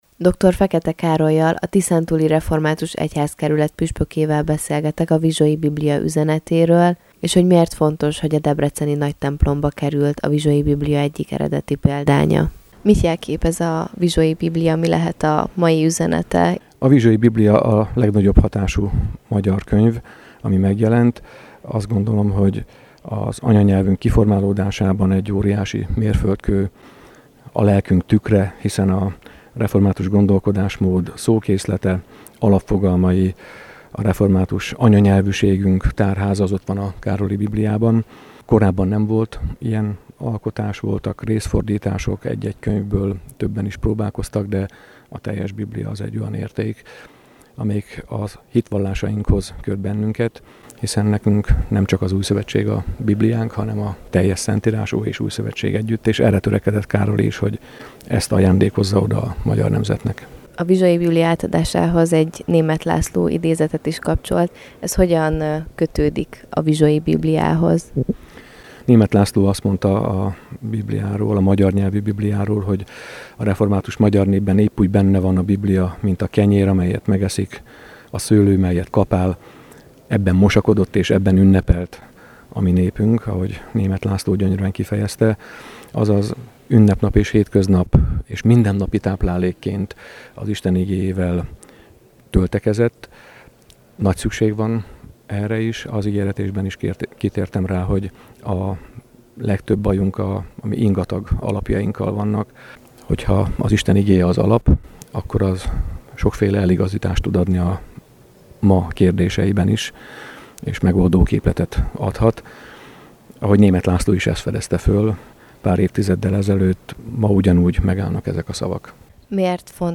Interjú: